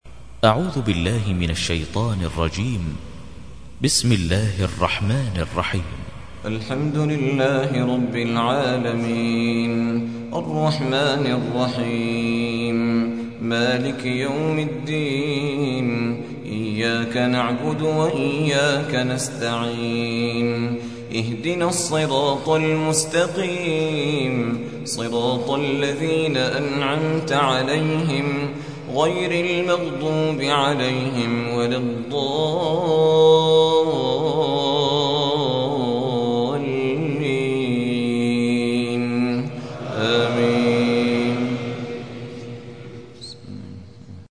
1. سورة الفاتحة / القارئ
القرآن الكريم